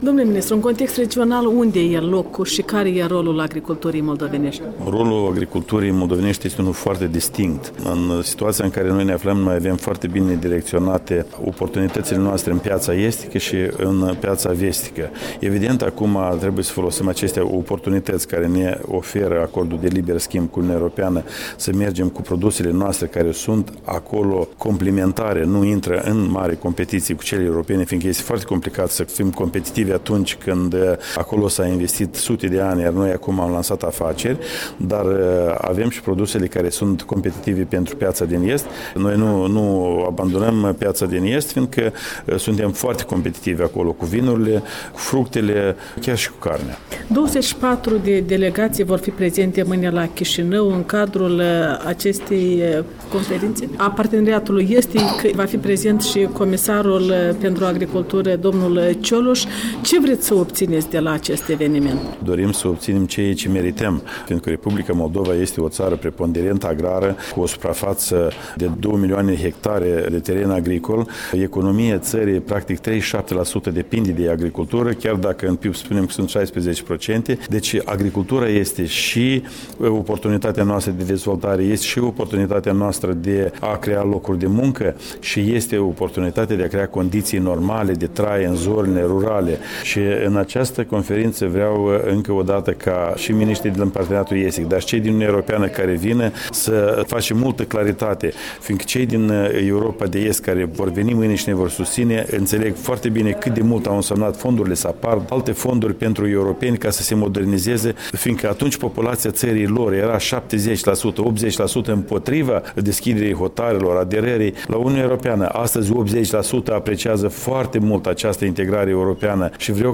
Interviu cu ministrul Vasile Bumacov